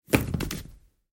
impact-big.ogg